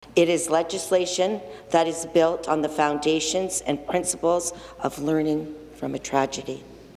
Ontario NDP Member of Provincial Parliament Jennie Stevens also spoke on Bill-99.